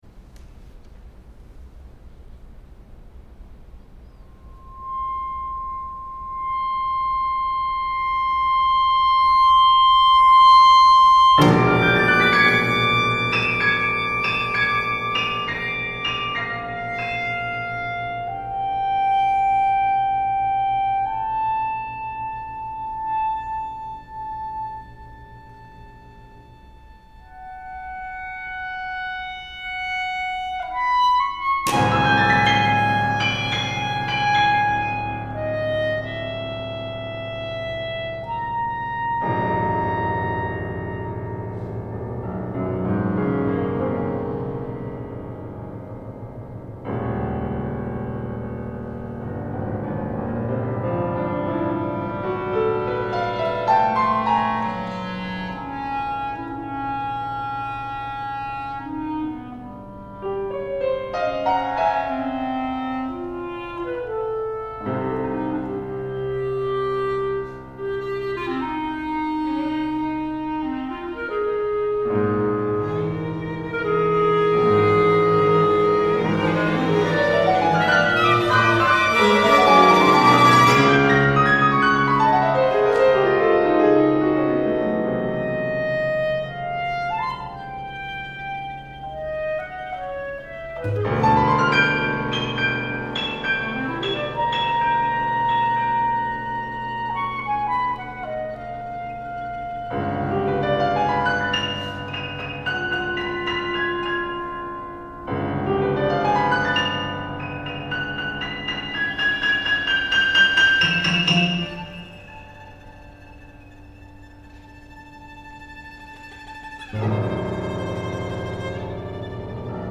Instrumentation: clarinet, violin, cello and piano